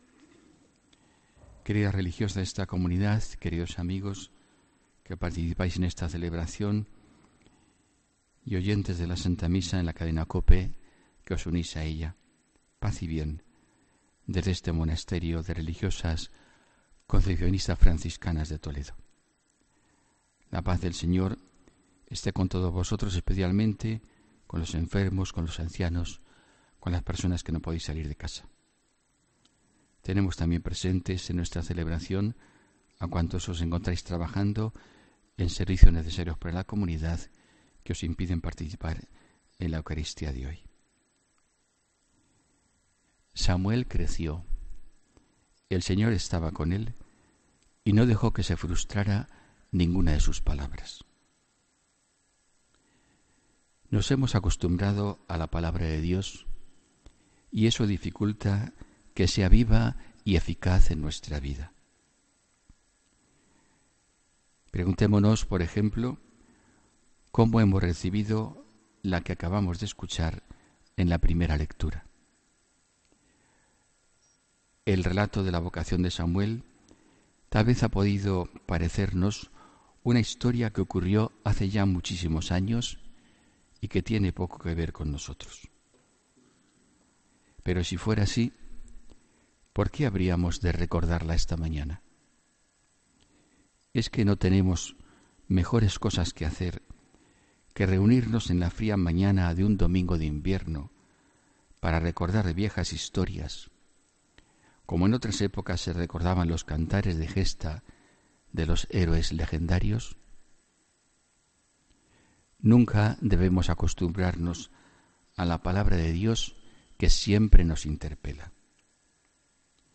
HOMILÍA 14 ENERO 2018